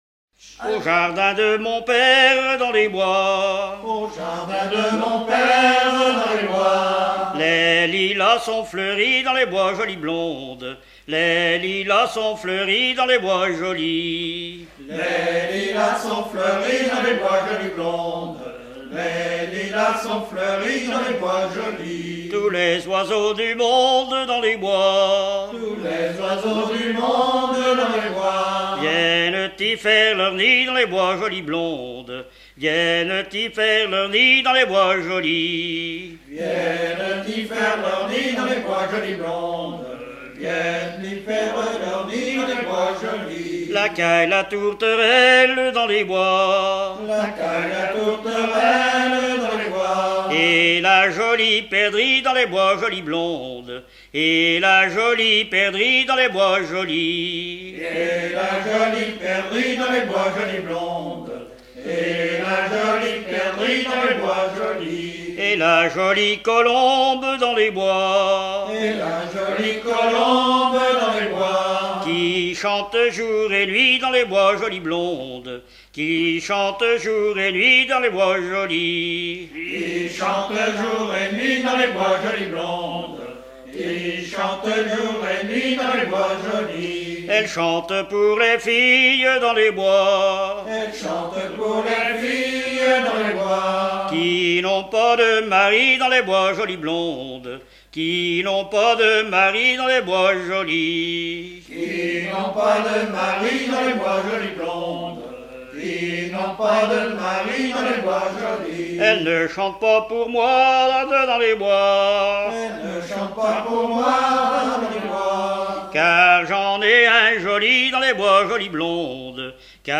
Fonction d'après l'analyste gestuel : à marcher
Genre laisse